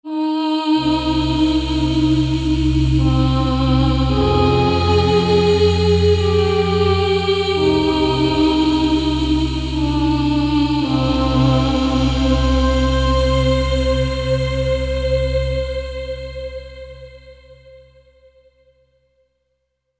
A large collection of mystical Choir and Vocal sounds that will take you on a magical journey.